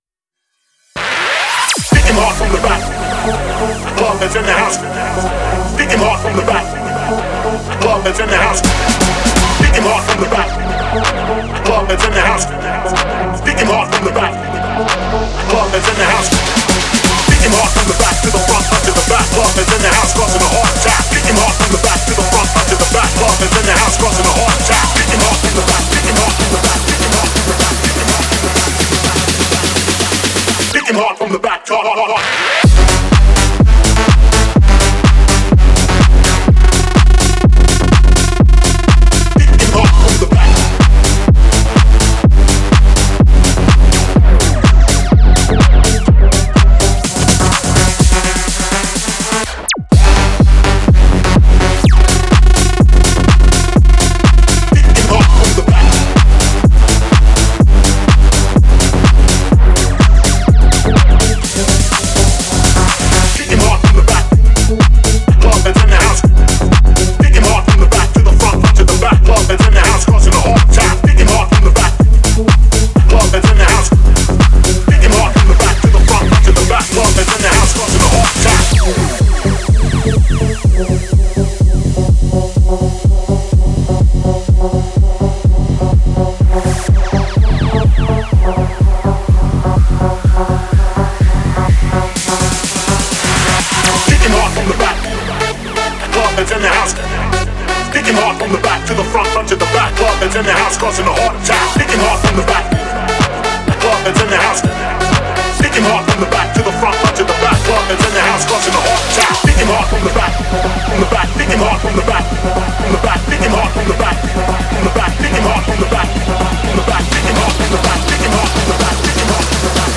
это энергичный трек в жанре EDM